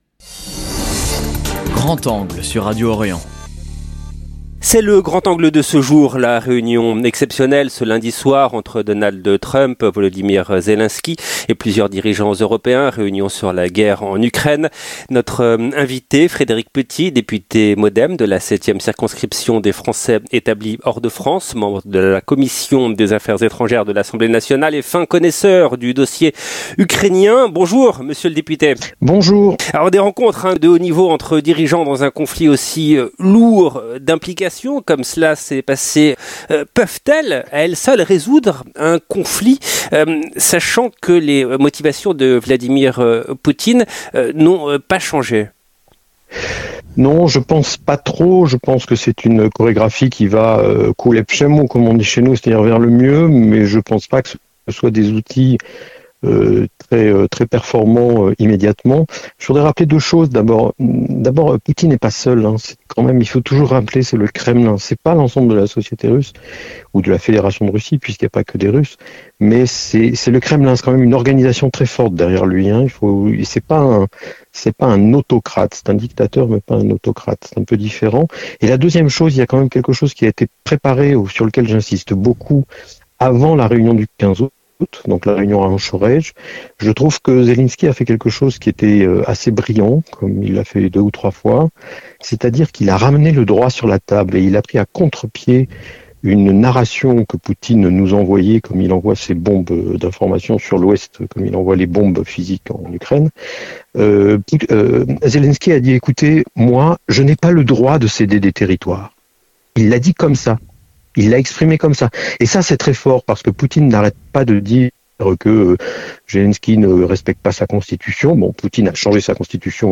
Notre invité , Frédéric Petit : député MoDem de la 7 ème circonscription des Français établis hors de France, membre de la commission des affaires étrangères, fin connaisseur du dossier ukrainien. Nous lui avons notamment demandé si des rencontres de haut niveau entre dirigeants de différents pays dans un conflit lourd d’implications peuvent à elles seuls résoudre un conflit sachant que les motivations de Vladimir poutine n’ont pas changé. 0:00 11 min 19 sec